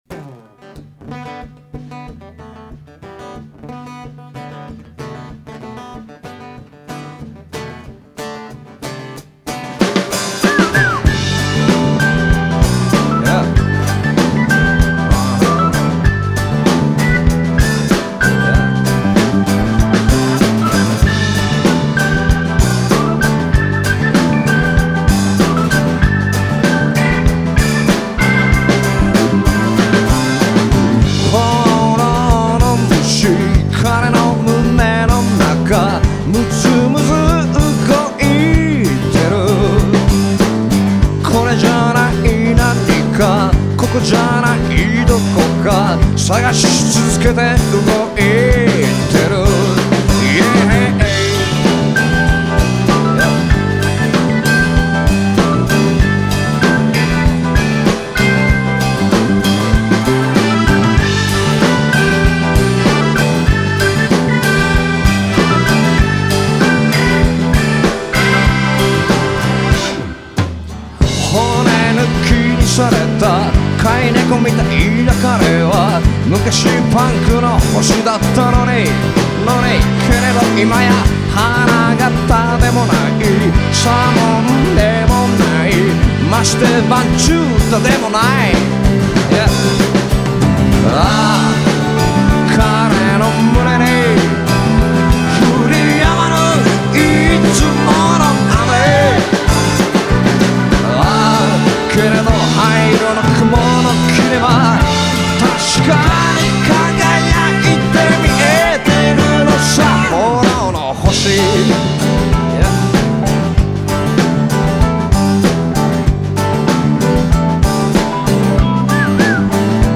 間奏のキーボード、ソウルフラワーぽく聞こえるのは俺だけでしょうか？